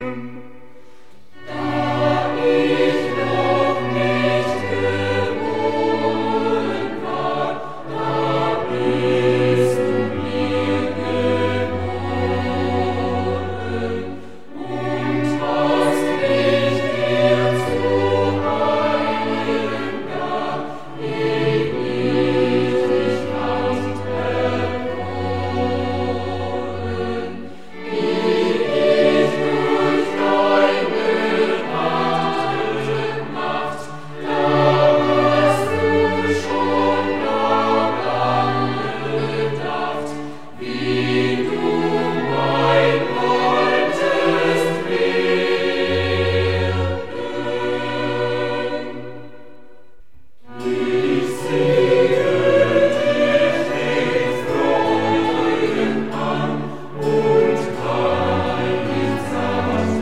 Choräle & Heilslieder, Instrumental